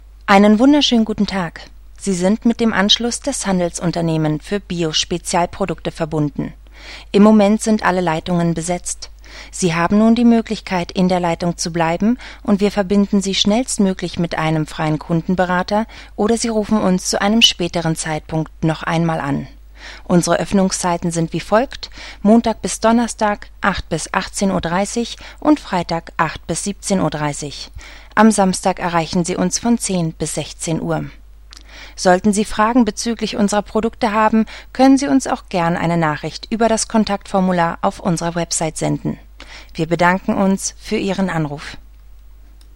Off-Sprecherin für diverse Beiträge und Monatsrückblicke der Medienwelt, Lesungen, Synchronsprecherin, Rezitationen
Sprechprobe: Industrie (Muttersprache):